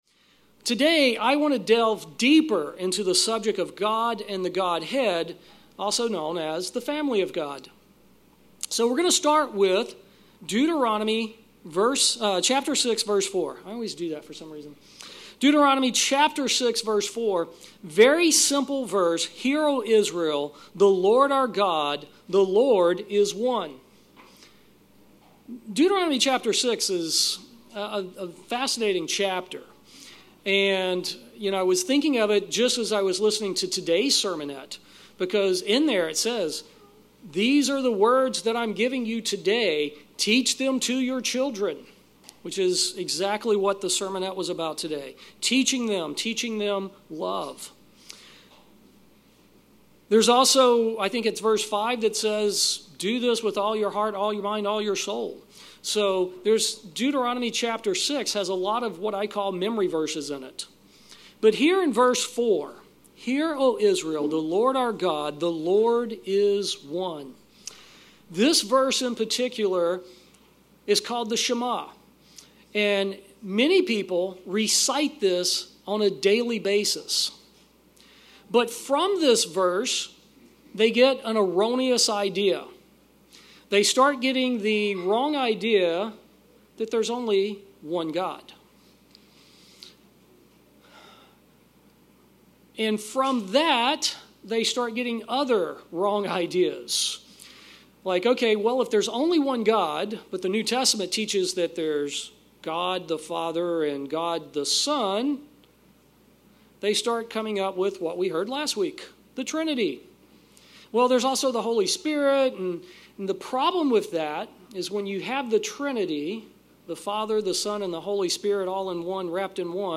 Sermons
Given in Fort Worth, TX